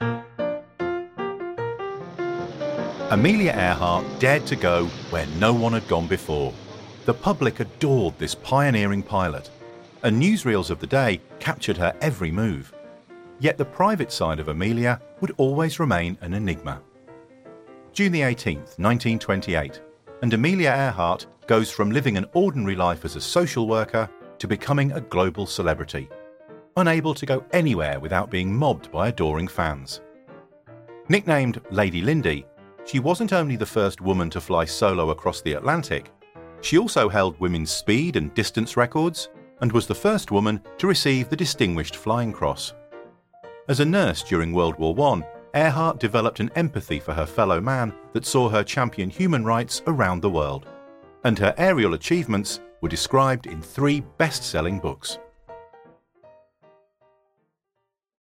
English (British)
Documentaries
Mic: SHURE SM7B